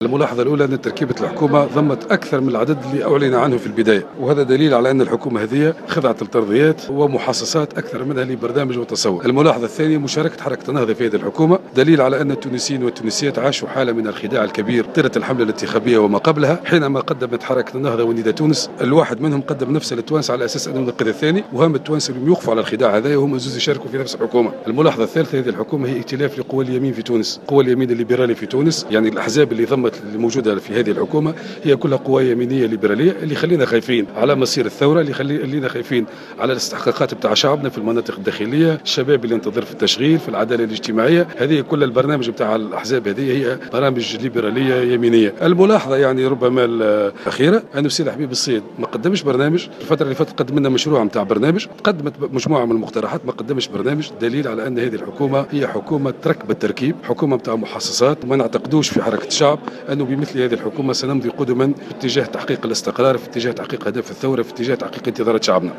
واعتبر المغزاوي في تصريح لمراسل جوهرة أف أم أن مشاركة النهضة في الحكومة الى جانب حركة نداء تونس دليل على خداع كل منهما للشعب التونسي طيلة الحملة الانتخابية وما قبلها.